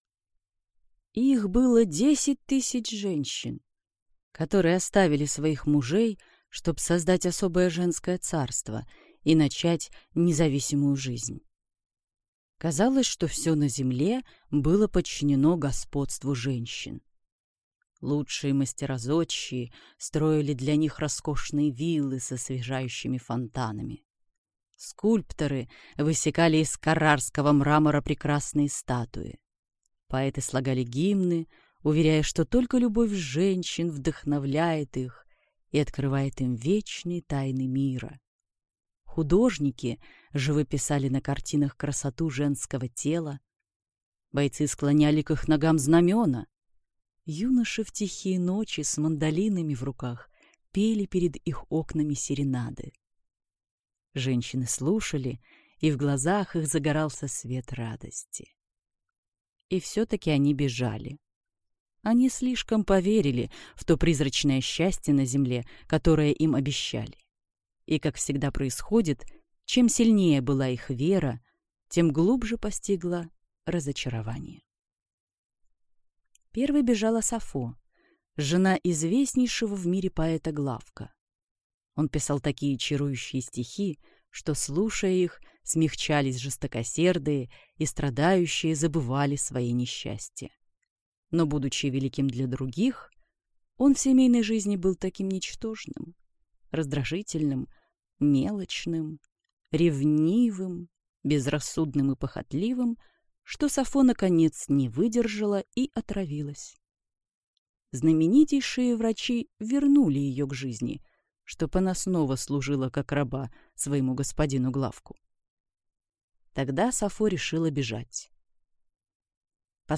Аудиокнига Остров Мессалины | Библиотека аудиокниг